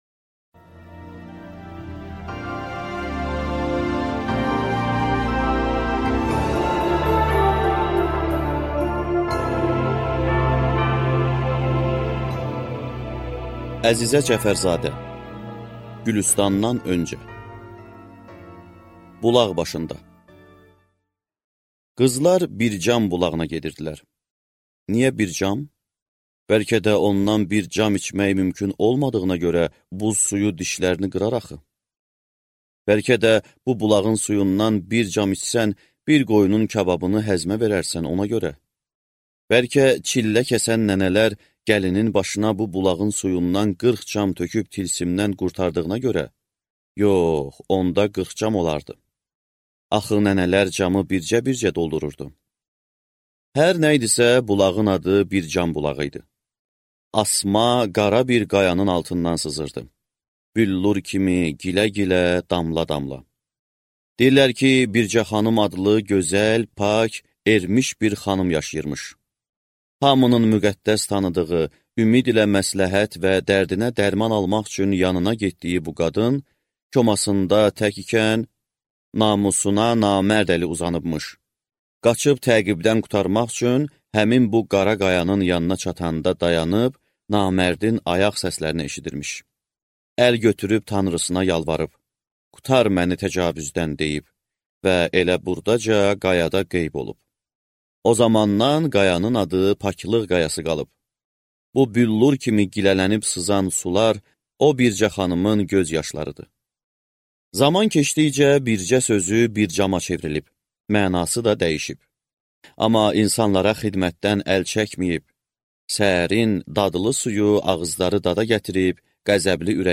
Аудиокнига Gülüstandan öncə | Библиотека аудиокниг